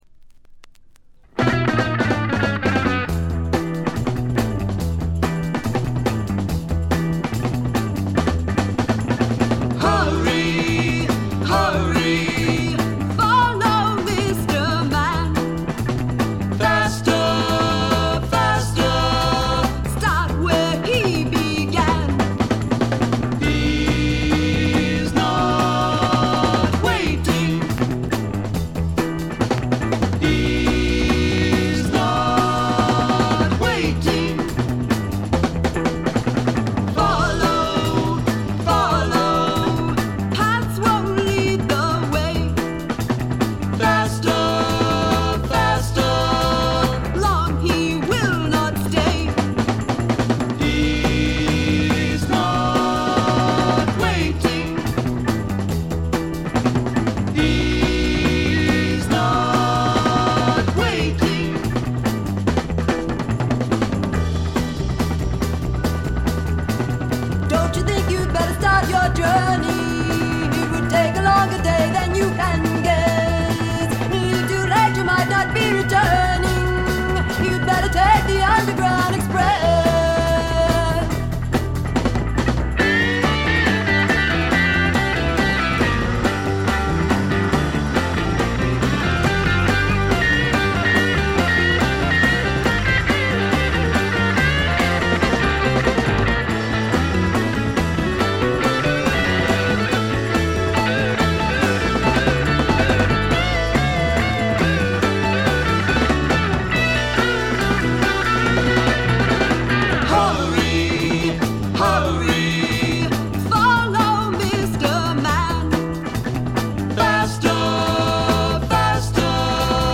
バックグラウンドノイズ、チリプチ多め大きめ。プツ音もそこそこ出ます。
マサチューセッツの5人組で男女ヴォーカルをフィーチャーしたジェファーソン・エアプレイン・タイプのバンドです。
試聴曲は現品からの取り込み音源です。